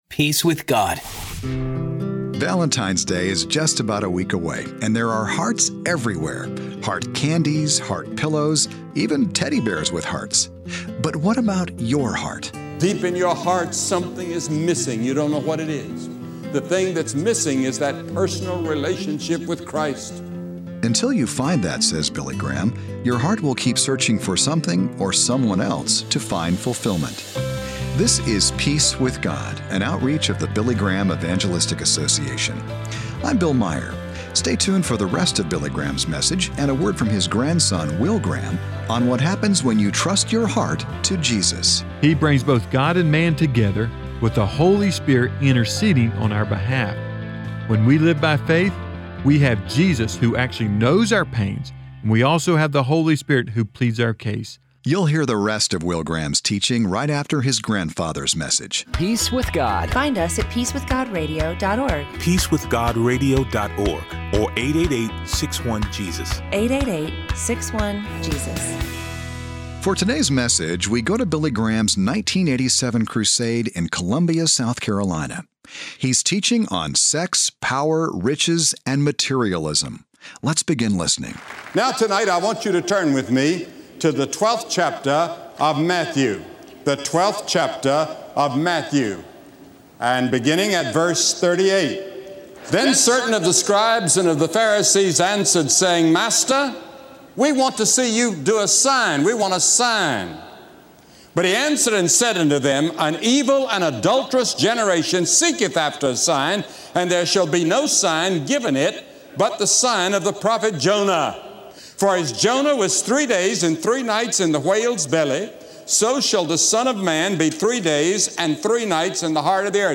Message from Columbia, South Carolina - 1987